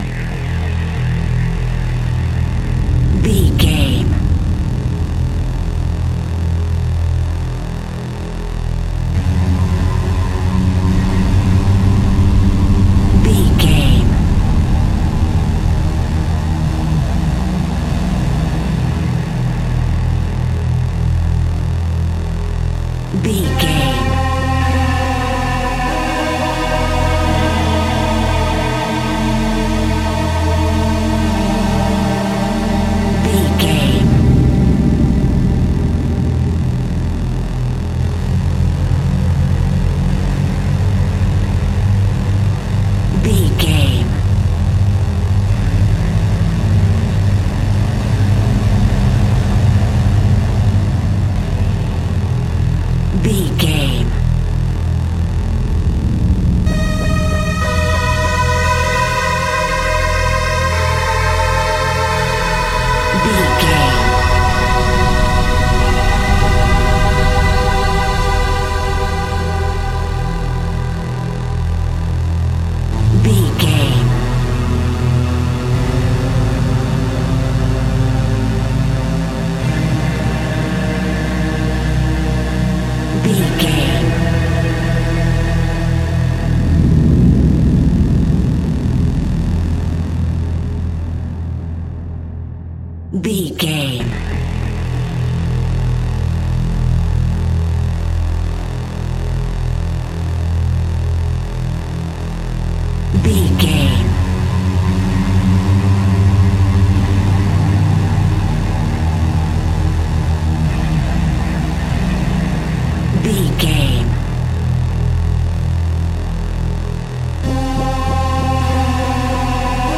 In-crescendo
Aeolian/Minor
Slow
scary
ominous
dark
haunting
eerie
synthesiser
electric guitar
strings
horror